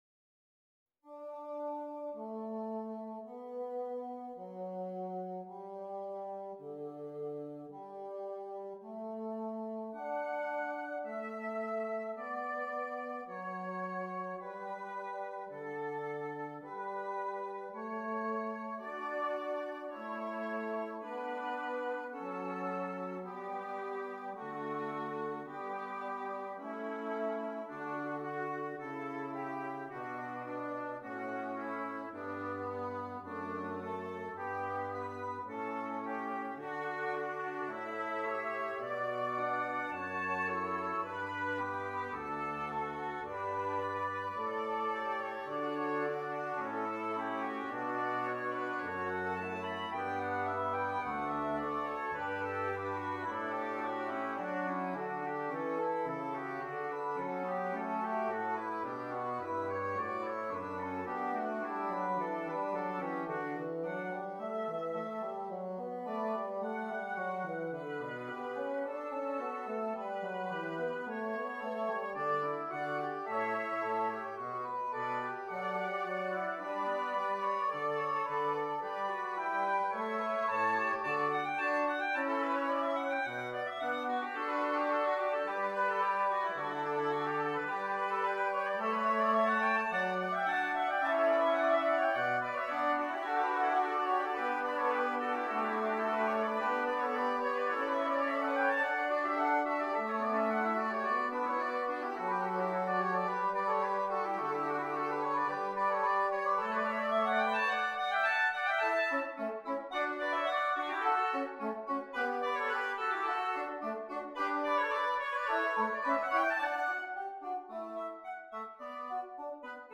Double Reed Ensemble